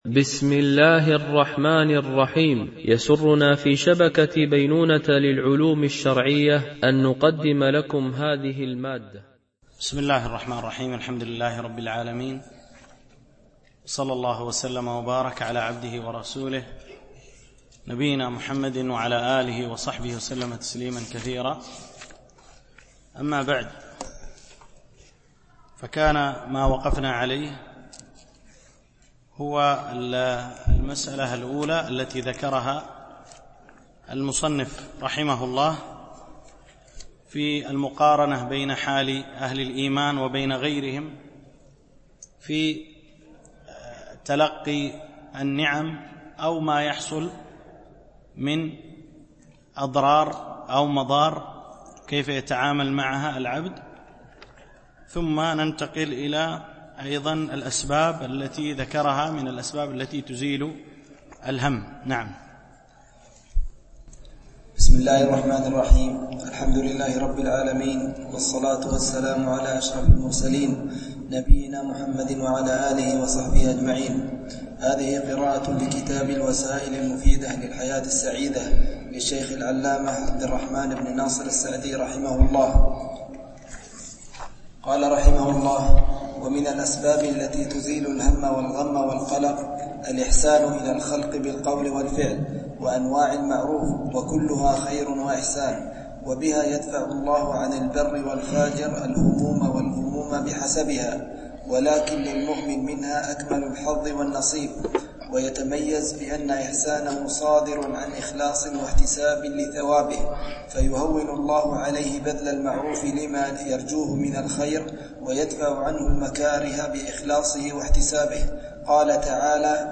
دورة علمية شرعية، بمسجد أم المؤمنين عائشة - دبي